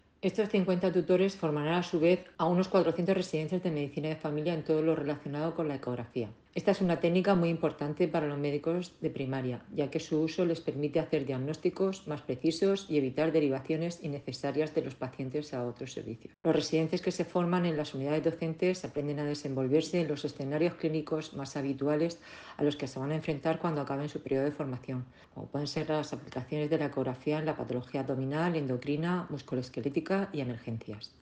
Declaraciones de la directora general de Atención Primaria, María José Marín, sobre la formación en ecografías y su uso en la consulta.